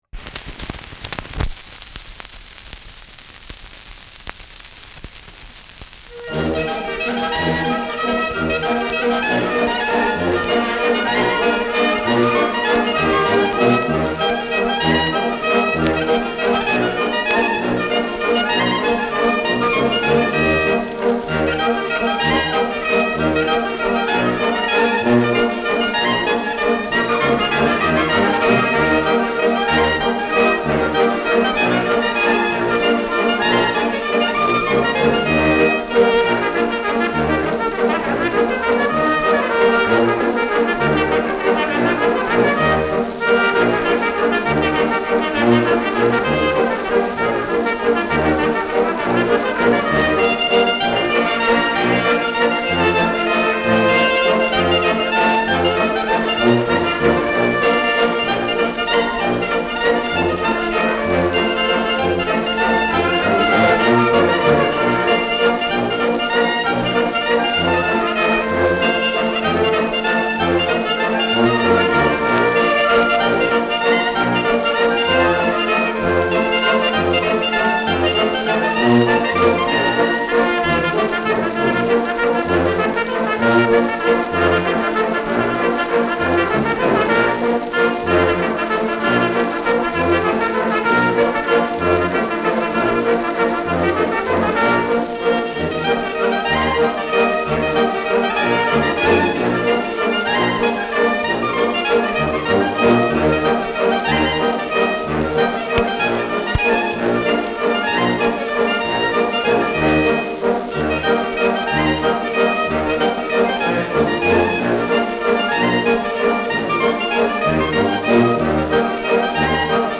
Schellackplatte
Vereinzelt leichtes Knacken
[München] (Aufnahmeort)